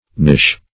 knish \knish\ (k'n[i^]sh"), n. (Russian and Jewish Cookery)